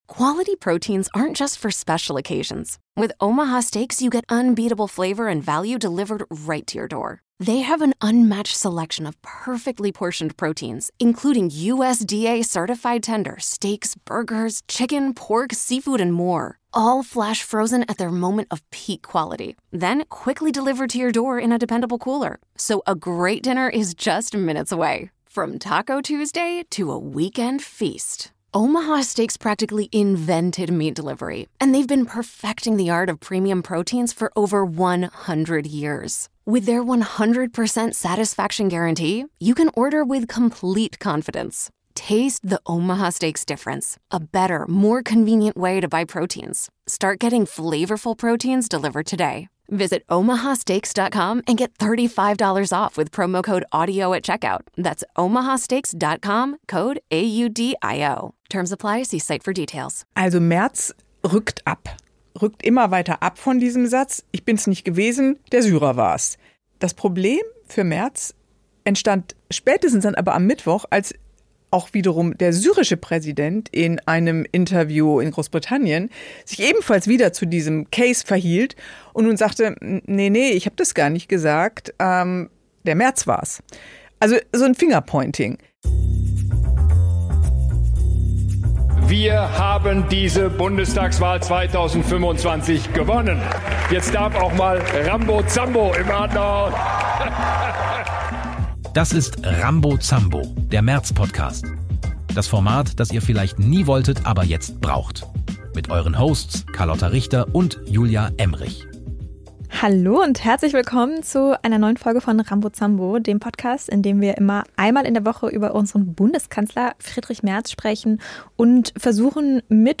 Zwei Frauen.